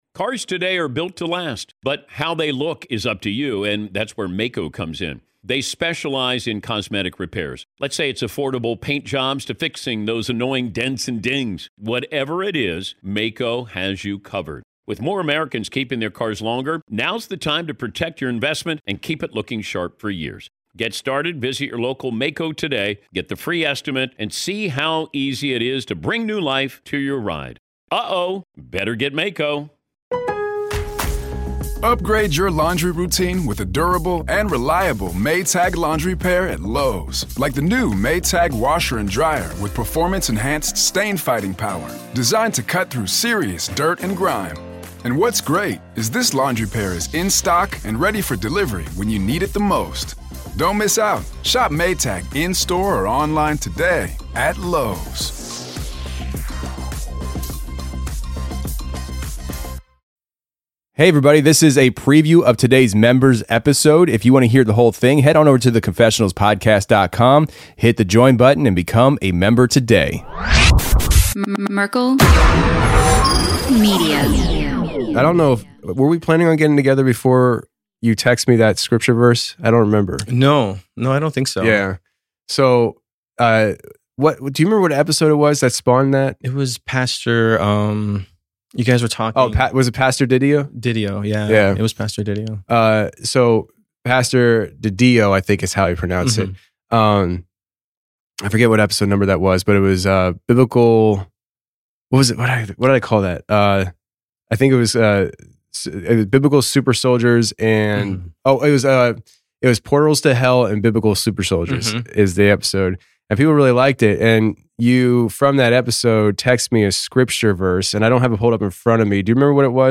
When he told me his findings and that he would be in town soon, I had to have him in the studio to record and share with you all what he turned up!